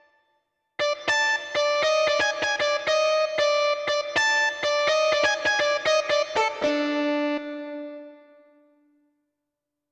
13. I SUONI - GLI STRUMENTI XG - GRUPPO "GUITAR"
21. Overdriven
XG-03-21-Overdriven.mp3